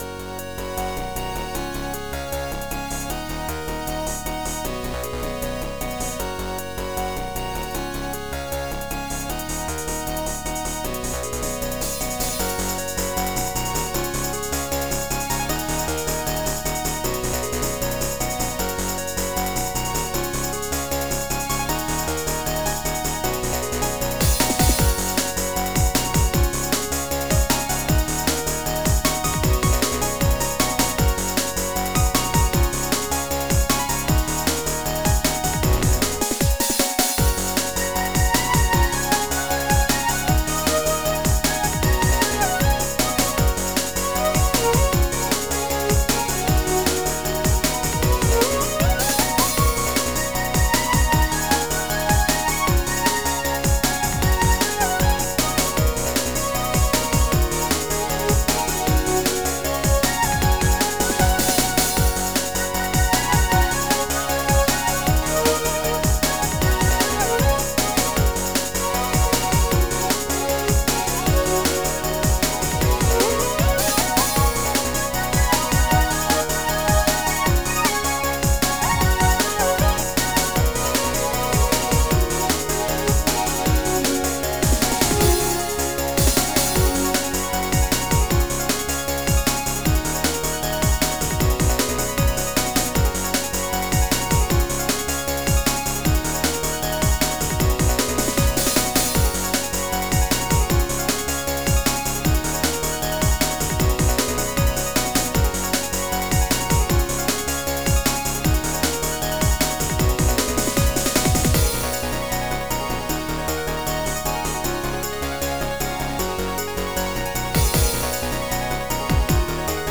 Stil: Chippop
Plus it has an 8-bit feel, and I've always loved 8-bit!